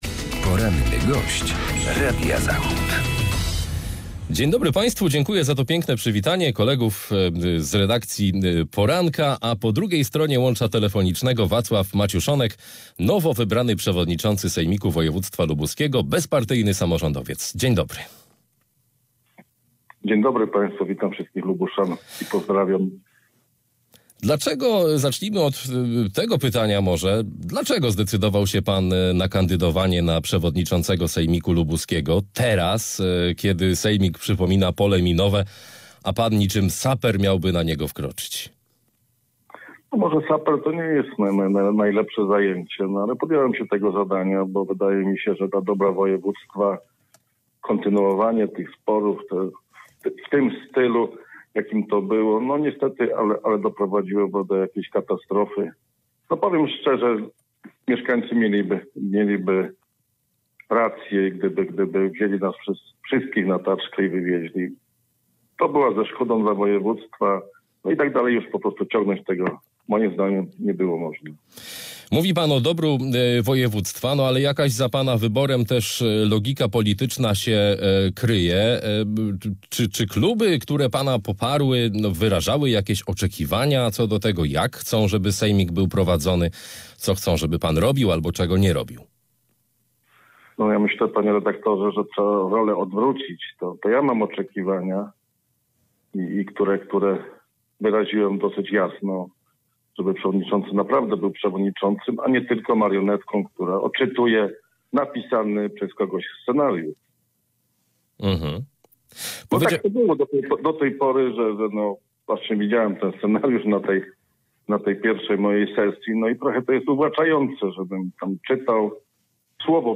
Wacław Maciuszonek, przewodniczący lubuskiego sejmiku